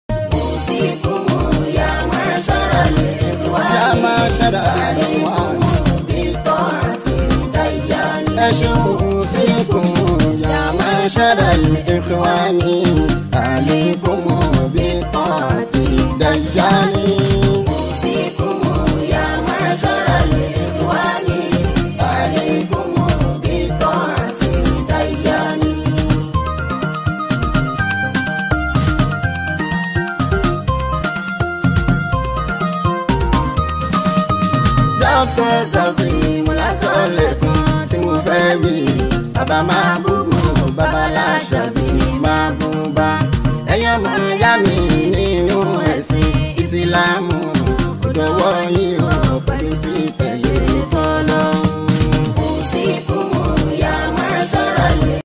Renowned Islamic singer
Known for his powerful vocals and thought-provoking lyrics
blends tradition with modern rhythm
With his signature Fuji-Islamic fusion style